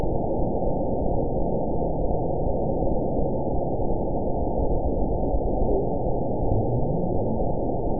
event 912307 date 03/23/22 time 22:58:29 GMT (3 years, 1 month ago) score 9.46 location TSS-AB04 detected by nrw target species NRW annotations +NRW Spectrogram: Frequency (kHz) vs. Time (s) audio not available .wav